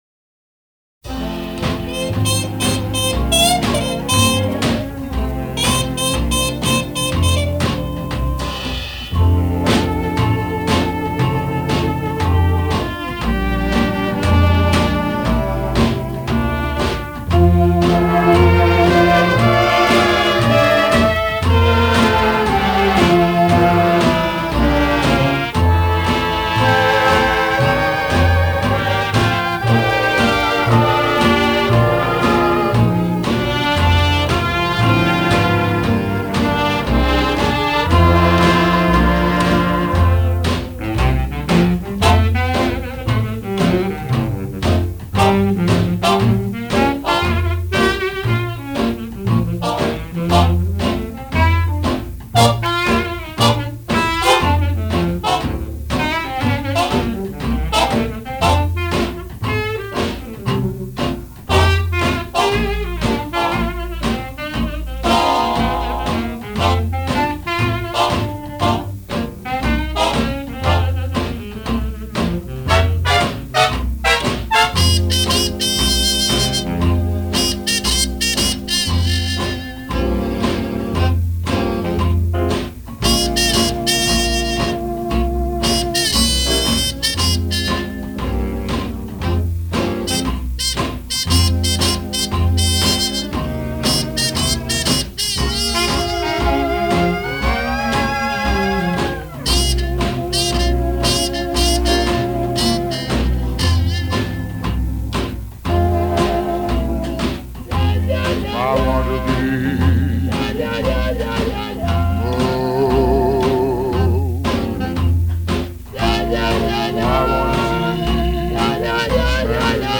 фокстрот - Оркестр под упр.